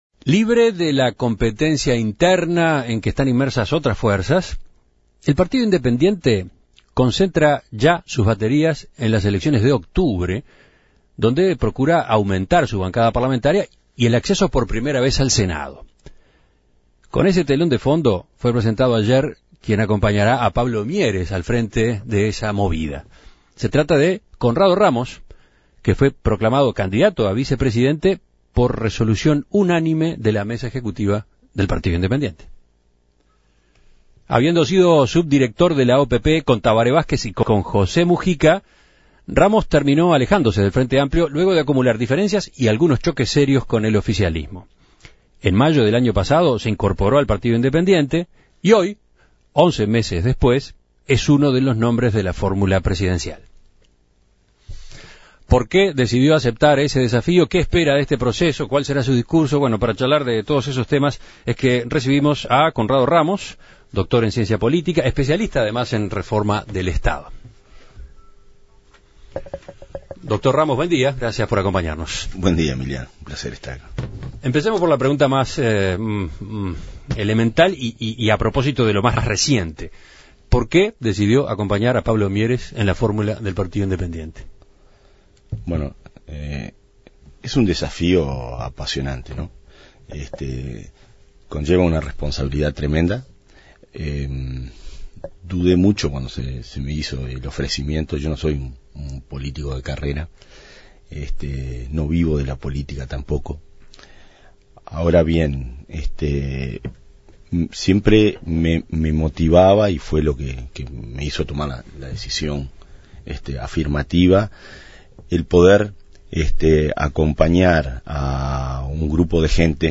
En diálogo con En Perspectiva, Ramos destacó la necesidad de dejar en claro que el PI representa "la otra izquierda".